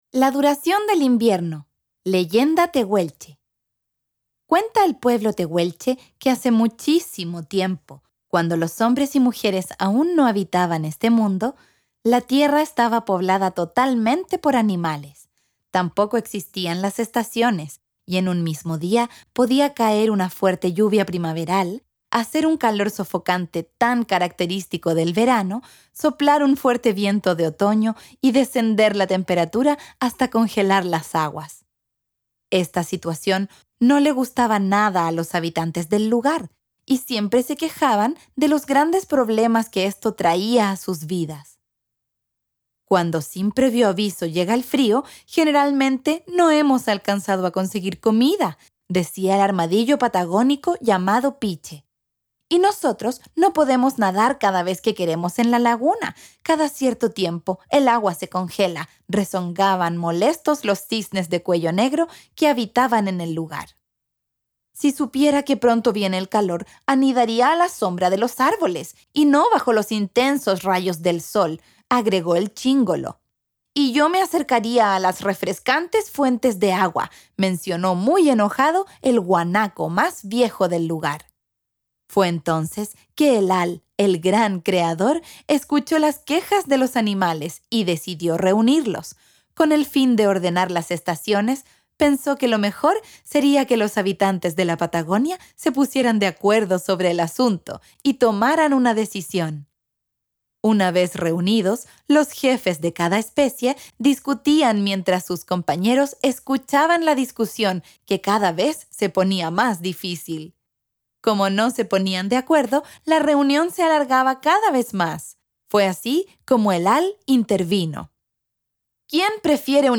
Audiocuento
Audiocuento “La duración del invierno” texto que rescata la ancestral leyenda tehuelche sobre el origen de las estaciones. A través de este relato, descubrirás cómo una disputa entre animales dio forma a la duración del invierno.